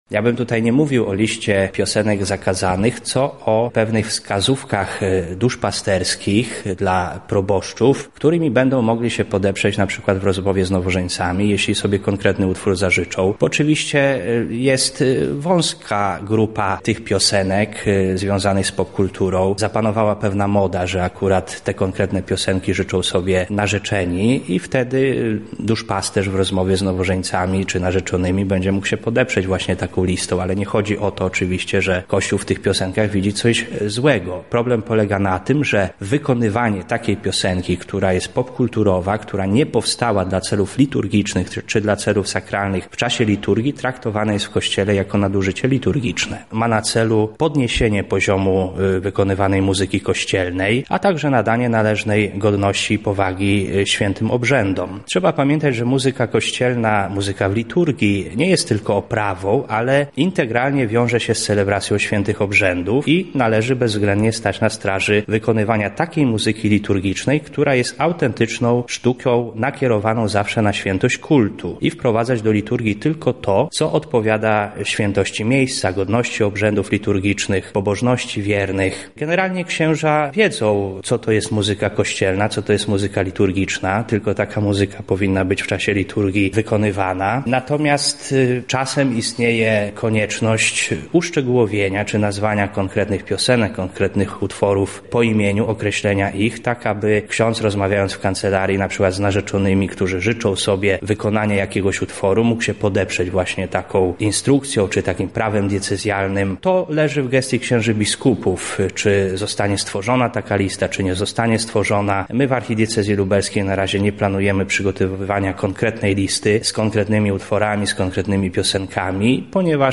rozmawiała